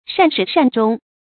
shàn shǐ shàn zhōng
善始善终发音
成语正音终，不能读作“zōnɡ”。